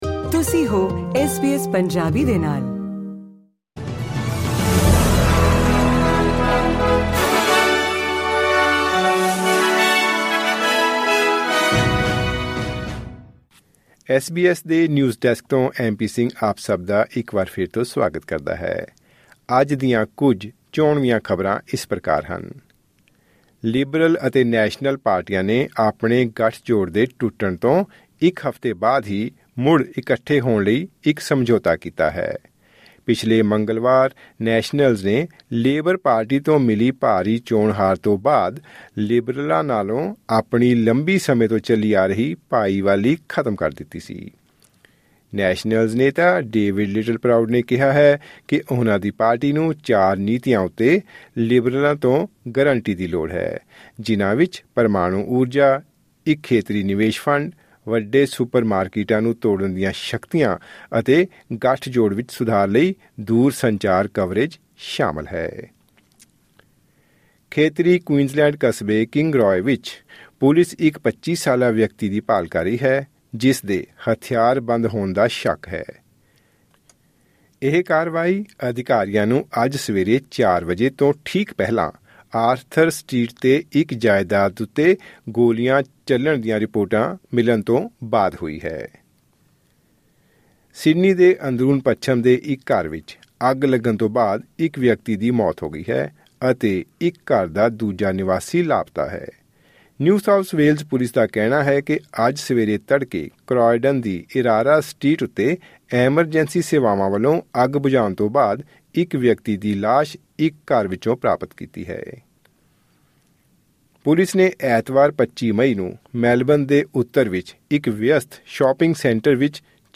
ਖਬਰਨਾਮਾਂ: ਲਿਬਰਲ ਅਤੇ ਨੈਸ਼ਨਲ ਪਾਰਟੀਆਂ ਨੇ ਤੋੜ ਵਿਛੋੜੇ ਦੇ ਇੱਕ ਹਫ਼ਤੇ ਬਾਅਦ ਹੀ ਮੁੜ ਇਕੱਠੇ ਹੋਣ ਲਈ ਕੀਤਾ ਸਮਝੌਤਾ